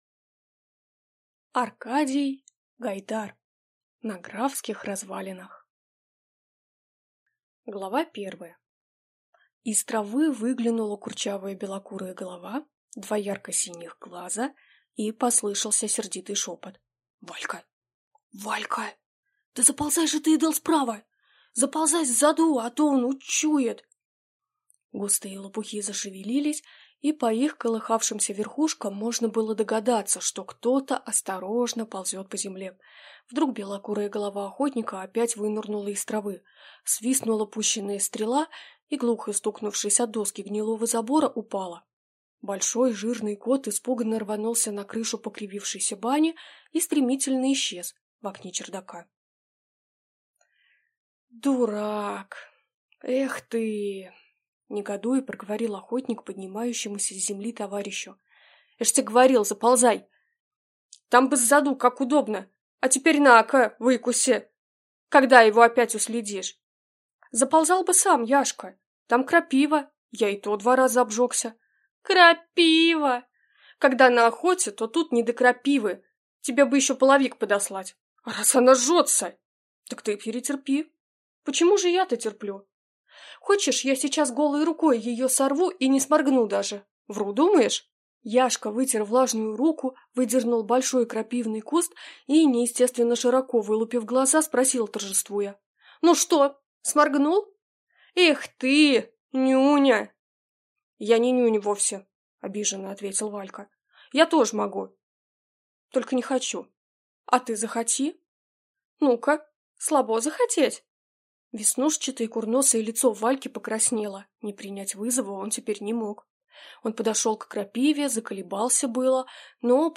Аудиокнига На графских развалинах | Библиотека аудиокниг